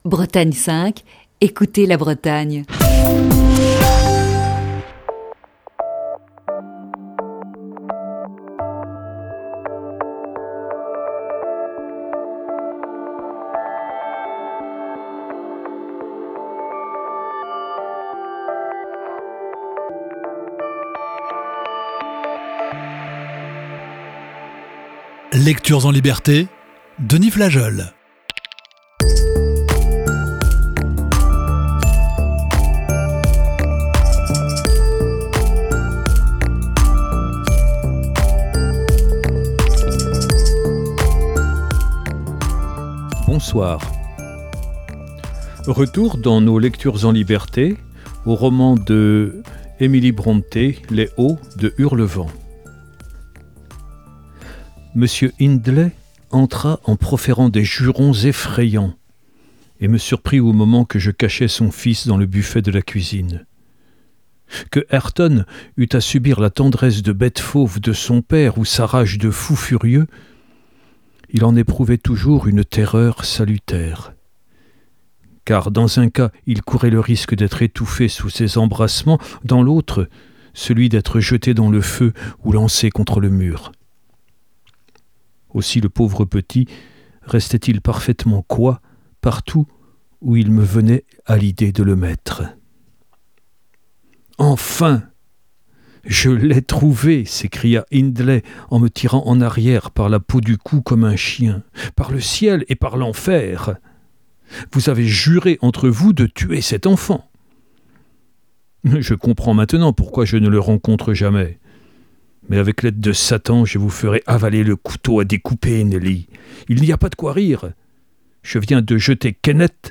Émission du 26 mai 2021.